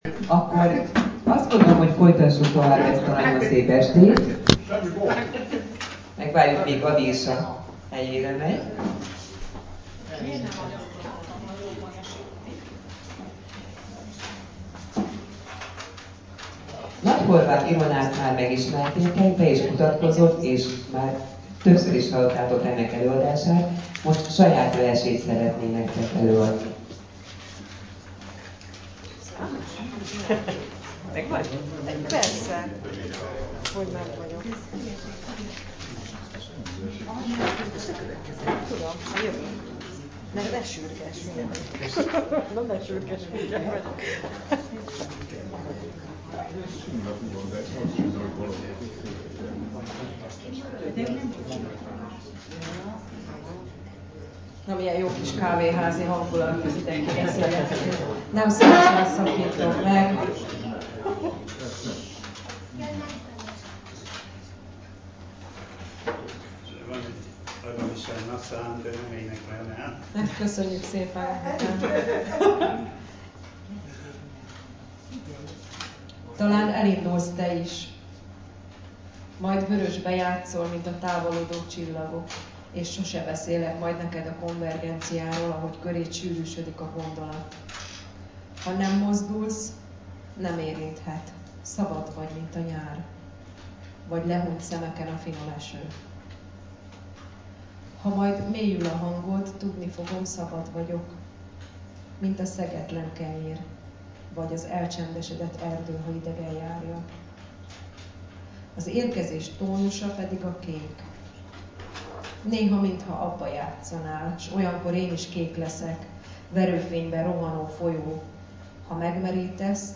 Toronyest Hangfelvétele 2019 Debrecen – 7torony Irodalmi Magazin